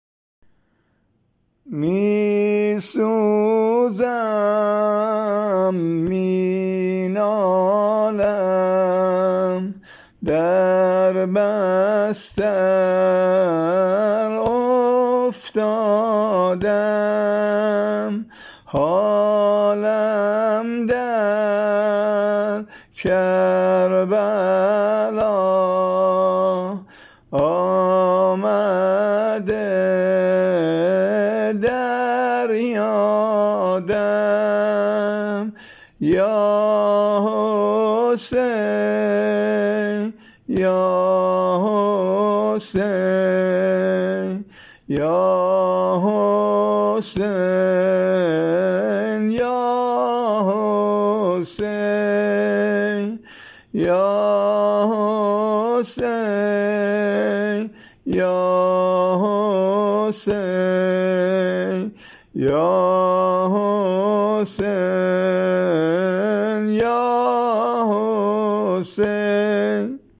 نوخه حضرت سجاد